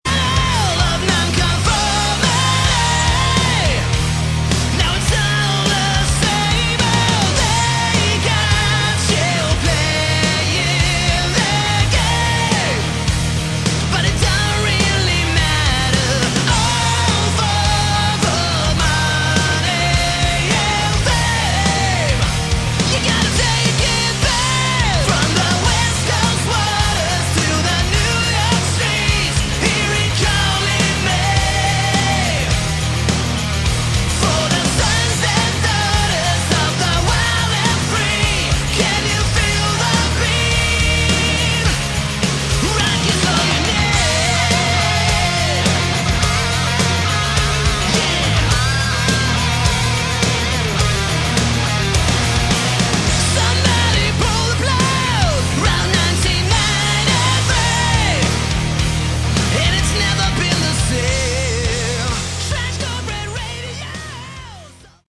Category: Hard Rock
Vocals
Bass
Drums
Guitar